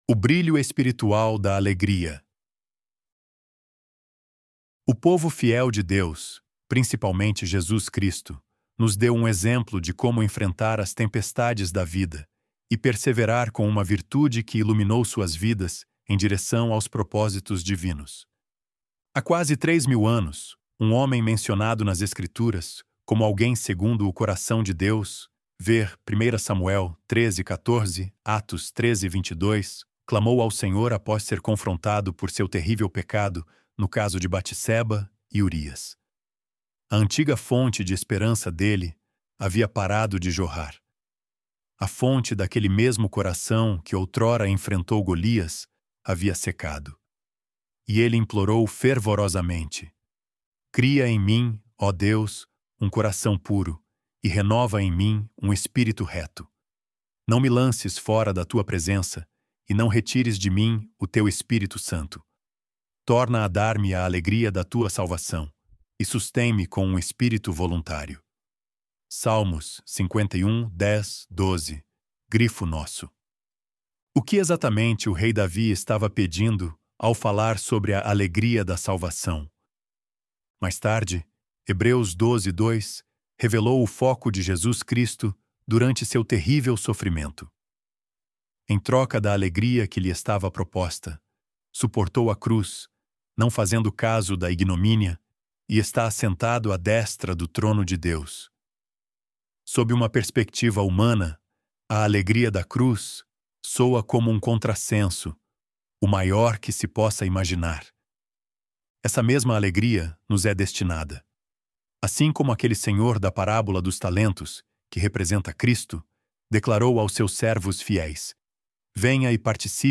ElevenLabs_O_Brilho_Espiritual_da_Alegria.mp3